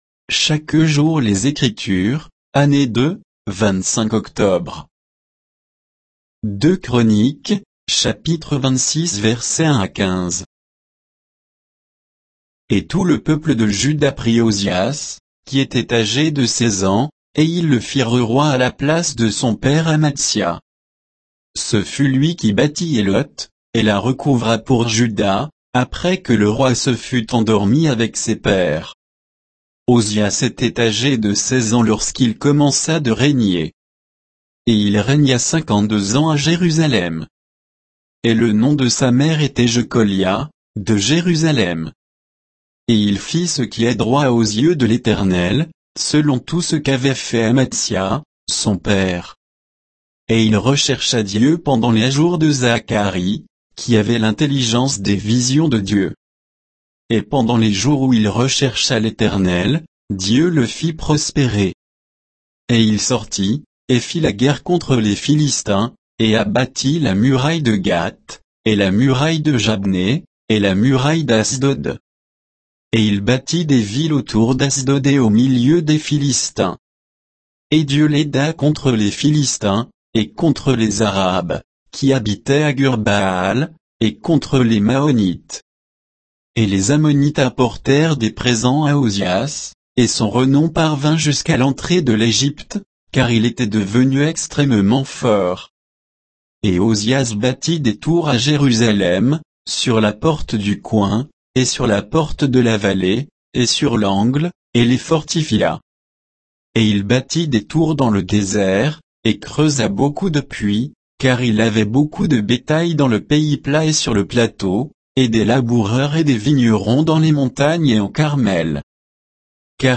Méditation quoditienne de Chaque jour les Écritures sur 2 Chroniques 26, 1 à 15